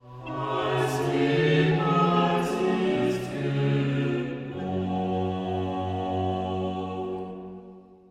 Uitgevoerd door Chamber Choir of Europe.
Bach-Choral_Es-stehn-vor-Gottes-Throne_BWV-309__Chamber-Choir-of-Europe__Schluss.mp3